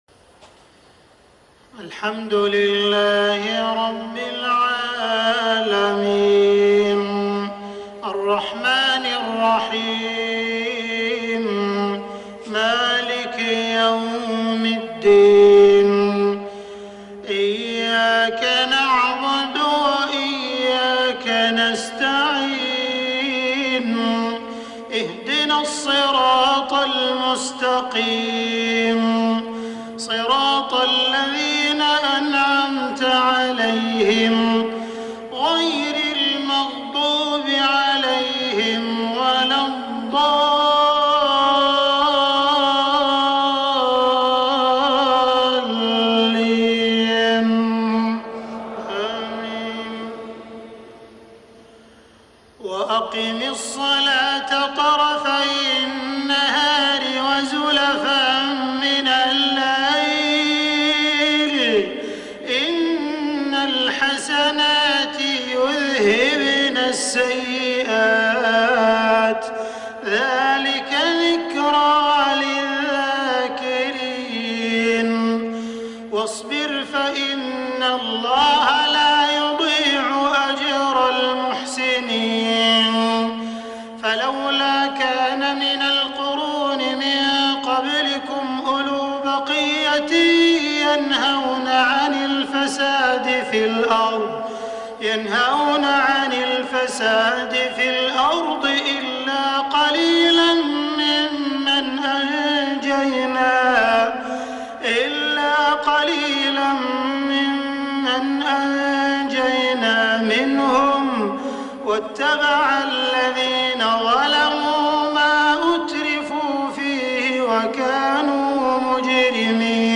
صلاة المغرب ١٢ محرم ١٤٣٤هـ من سورة هود | > 1434 🕋 > الفروض - تلاوات الحرمين